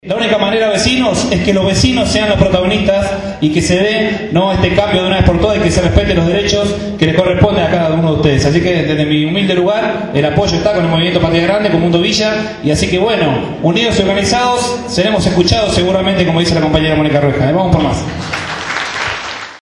El sábado 18 de agosto en la escuela Lafayette de Barracas se realizó un encuentro por el conflicto por la relocalización de las familias que viven en el camino de sirga.